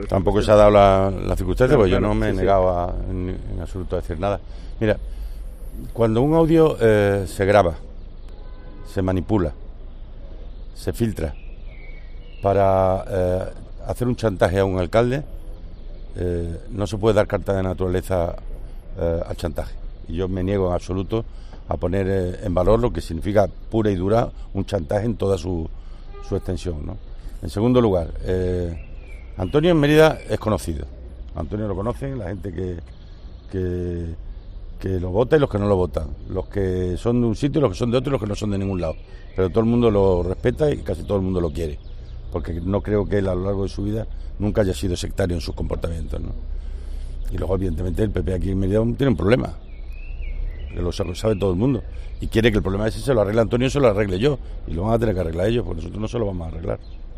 Guillermo Fernández Vara se ha pronunciado de esta forma a preguntas de los periodistas este miércoles en Mérida, donde ha asistido a una reunión del Club Senior Extremadura.